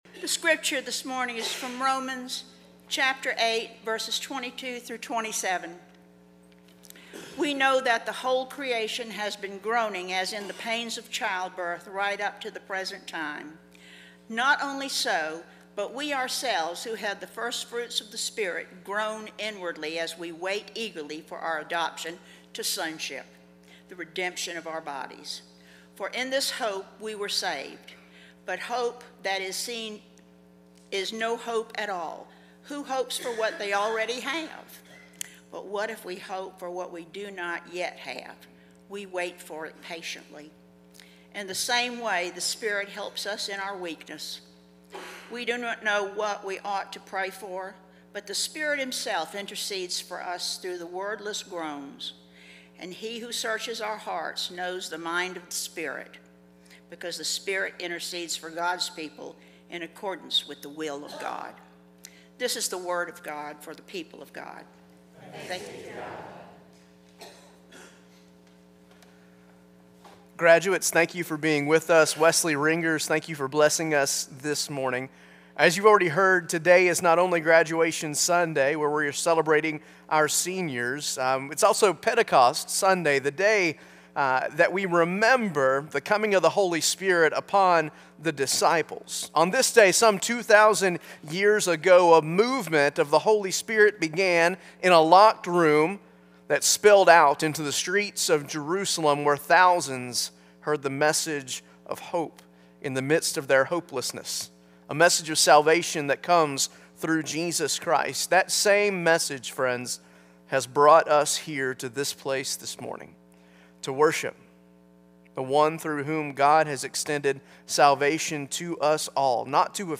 Passage: Romans 8:22-27 Service Type: Traditional Service